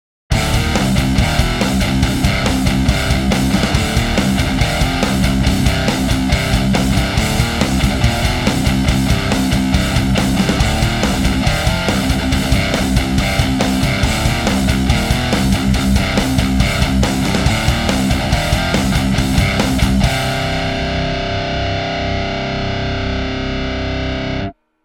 METAL Fortin 2.mp3